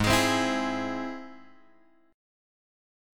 G#+ chord {4 x 6 5 5 4} chord
Gsharp-Augmented-Gsharp-4,x,6,5,5,4.m4a